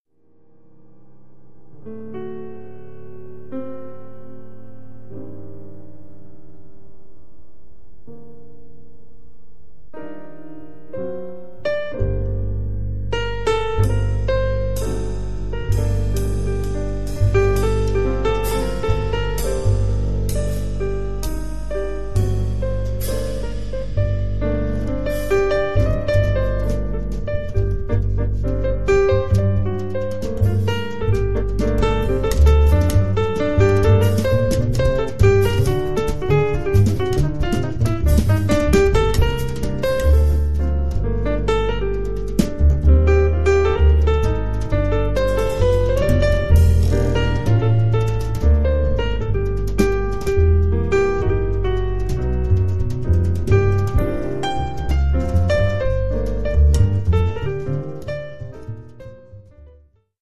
sax, clarinet